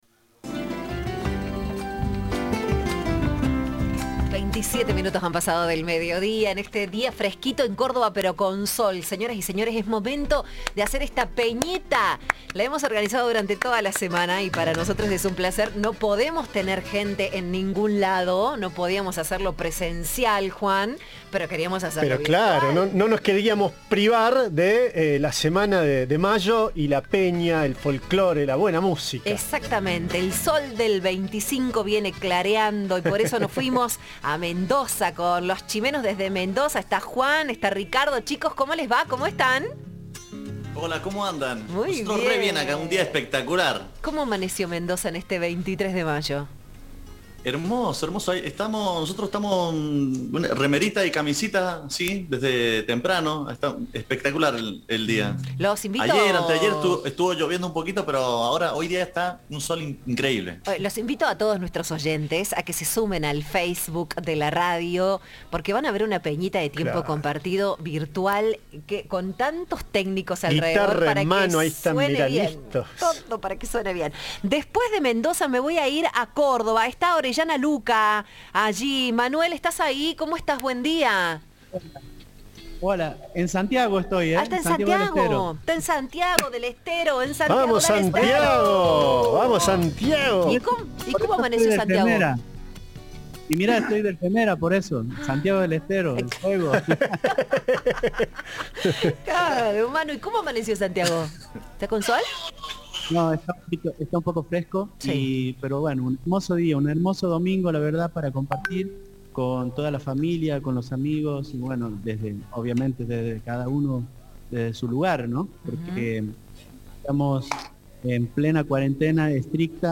Los Chimeno desde Mendoza, el dúo Orellana Lucca desde Santiago del Estero, y Los Alonsitos desde Corrientes se sumaron a un concierto online en las vísperas del 25 de mayo.